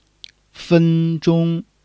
fen1-zhong1.mp3